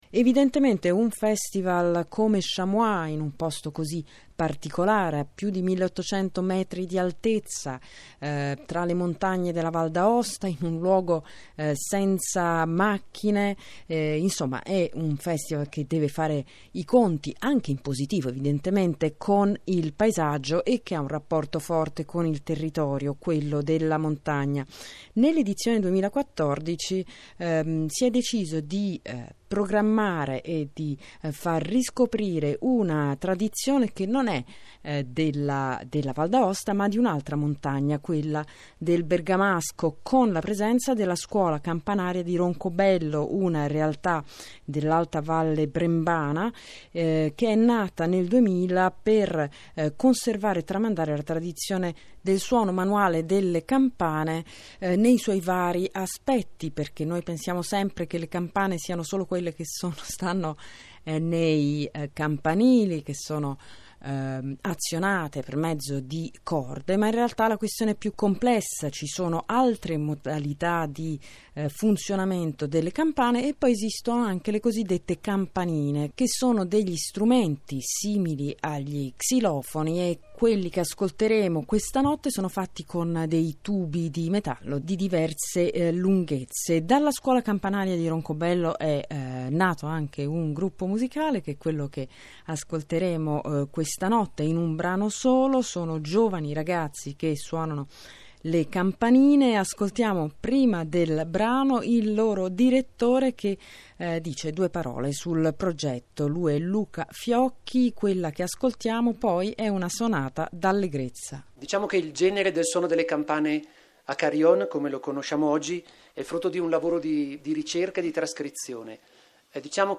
Presentiamo qui un estratto della trasmissione andata in onda il 10 aprile 2014
Mazurca
qui per ascoltare il frammento della trasmissione dedicato al suono delle campanine.